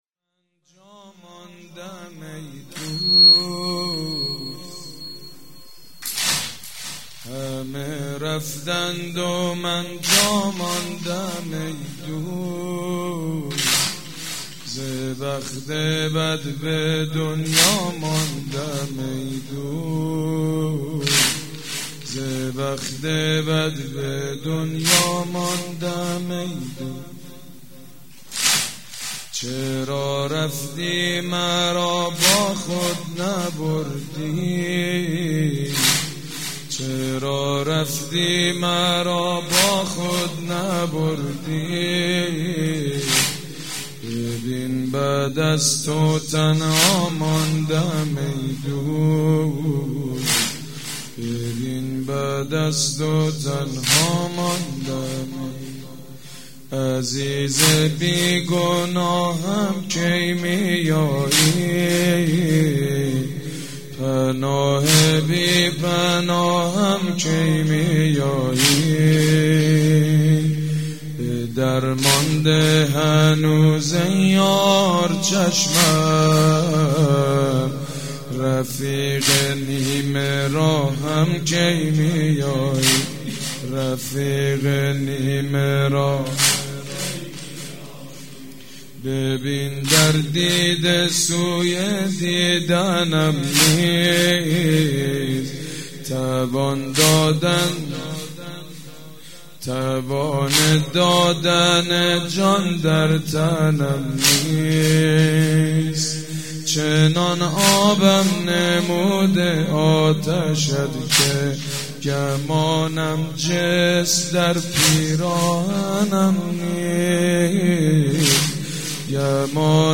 شب شهادت حضرت زينب(س)
مداح
حاج سید مجید بنی فاطمه